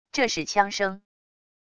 这是枪声wav音频